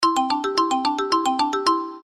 Короткие рингтоны
Рингтоны на смс и уведомления